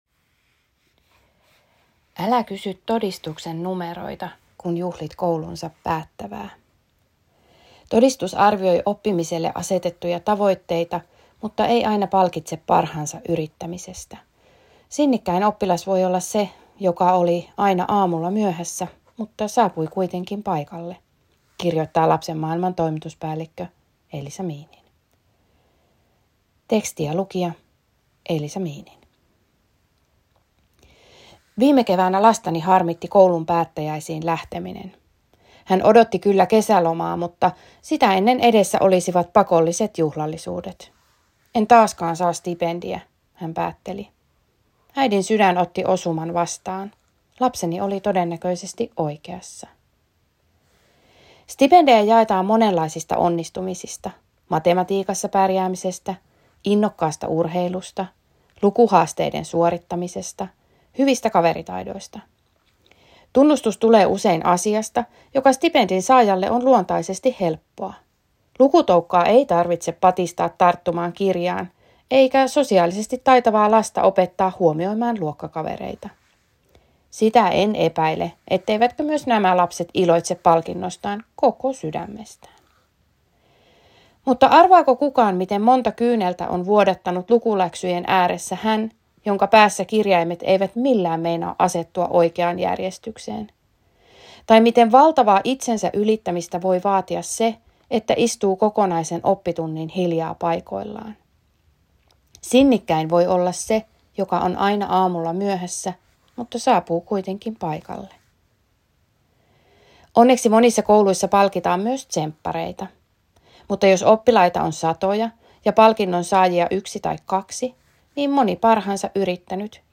kolumni-stipendit-luettuna.m4a